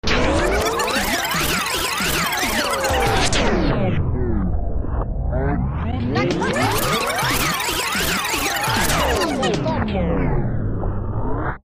На этой странице собраны аутентичные звуки виниловых пластинок: характерные потрескивания, теплый аналоговый звук и шумы, создающие особую атмосферу.
Шум вертушки на пластинке